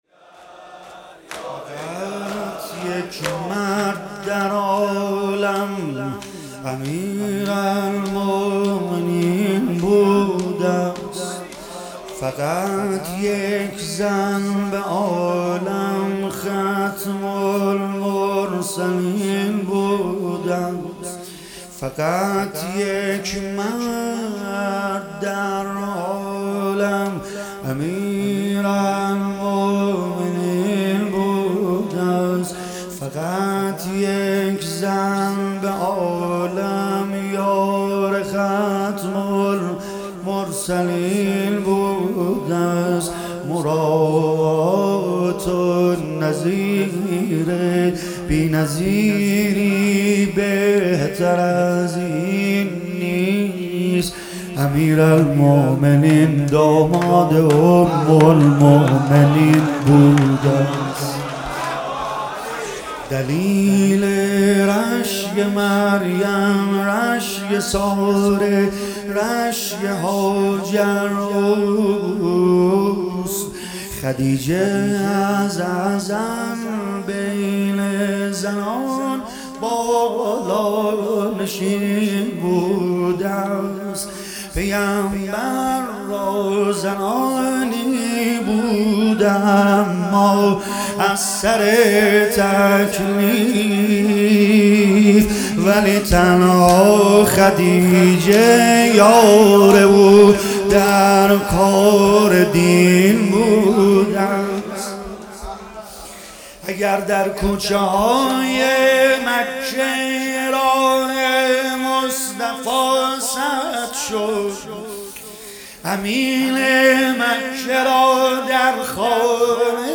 مراسم مناجات ماه مبارک رمضان 1445 – شب یازدهم – 2 فروردین 1403